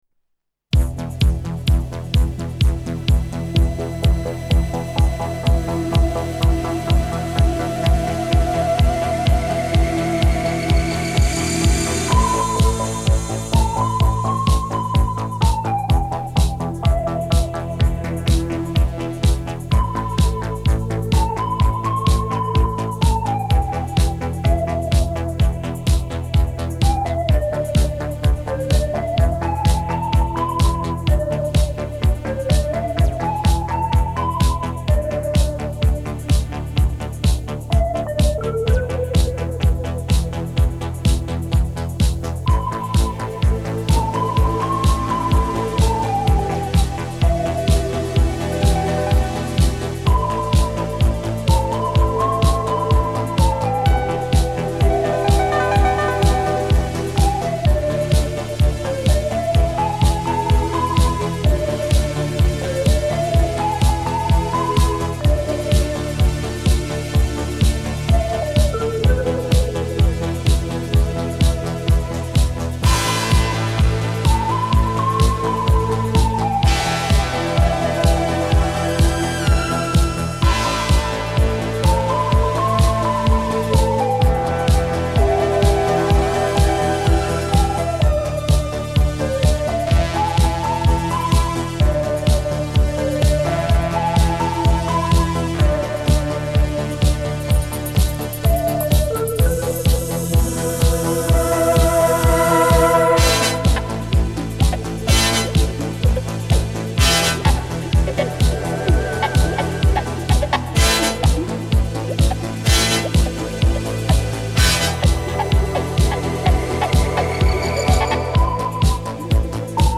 연주곡